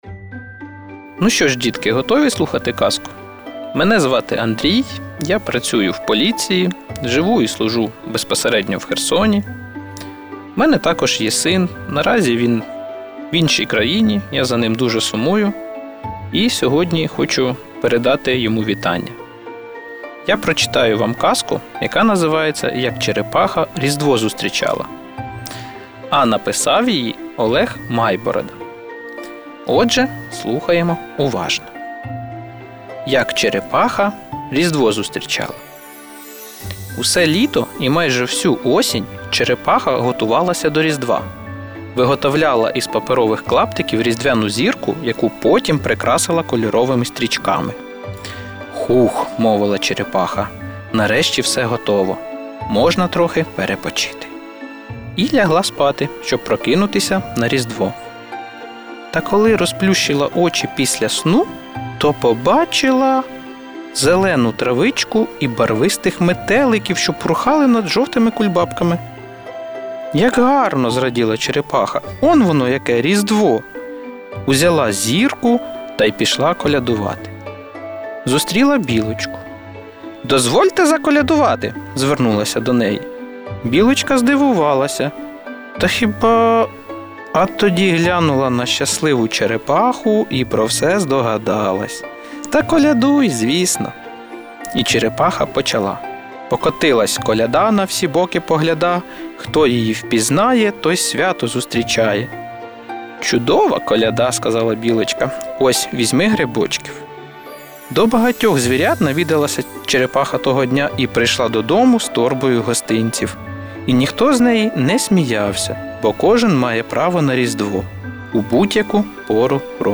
З  6 по 25 грудня увімкни радіо «Х.ОН», аби разом з дітьми почути наш щоденний Різдвяний проєкт – «Казка від тата». Історії від сучасних українських авторів зачитають батьки, які попри будь-яку відстань завжди поруч.